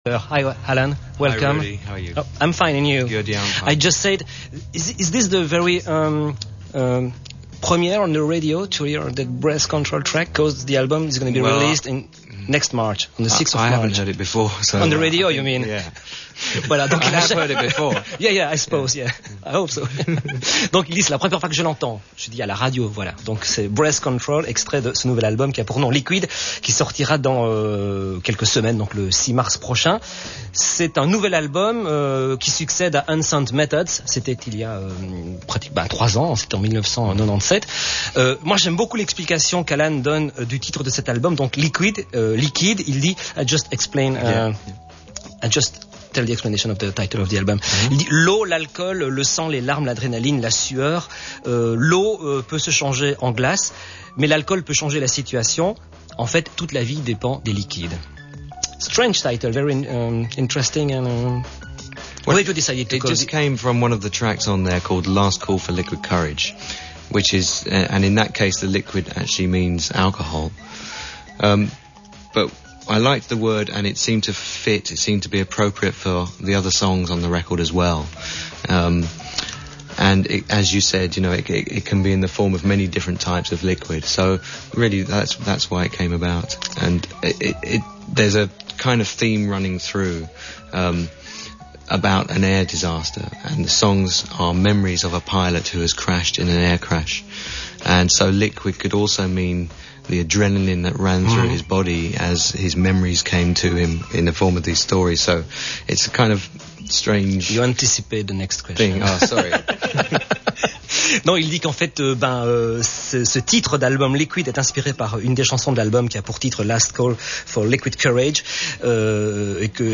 This included a recorded interview for the main Flemish radio station. listen to Radio 21 interview [mp3 file] continue :